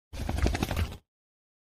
Tiếng Chó Vẩy tai, Lắc giũ rung mình… để loại bỏ nước, bụi bẩn…
Thể loại: Tiếng động
tieng-cho-vay-tai-lac-giu-rung-minh-de-loai-bo-nuoc-bui-ban-www_tiengdong_com.mp3